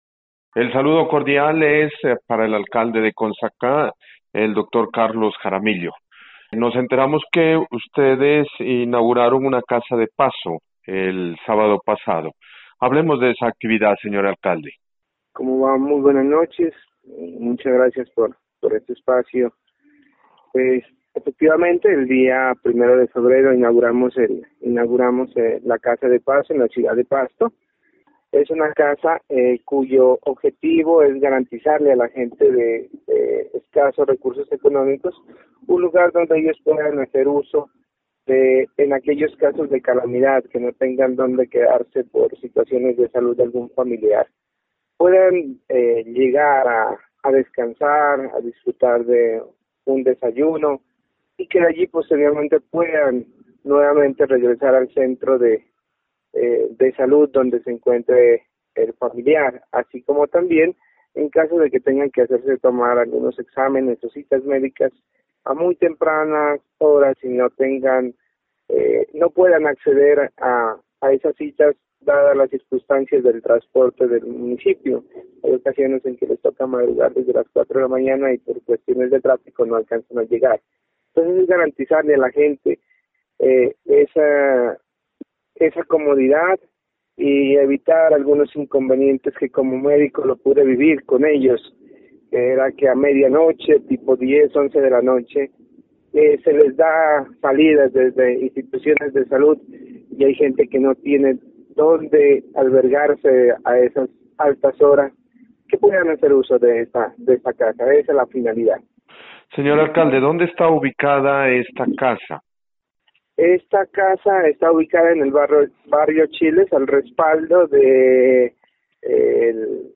Entrevista con el alcalde de Consacá: